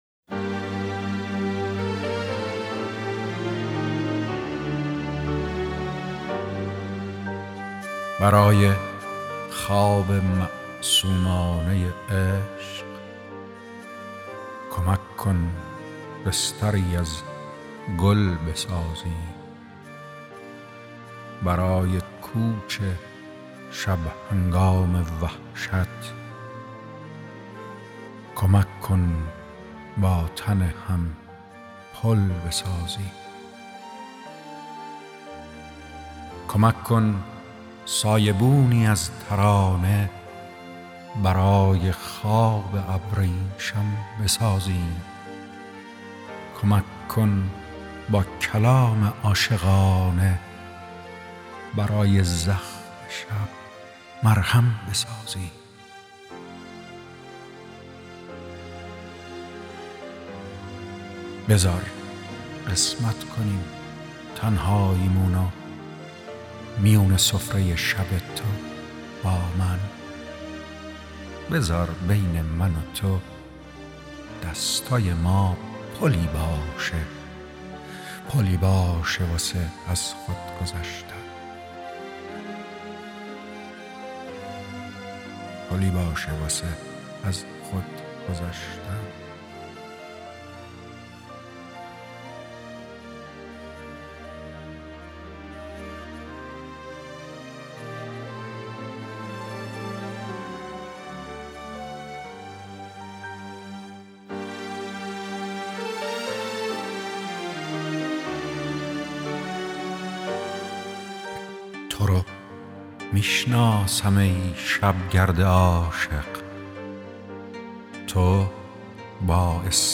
دانلود دکلمه پل با صدای ایرج جنتی عطایی با متن دکلمه
گوینده :   [ایرج جنتی عطایی]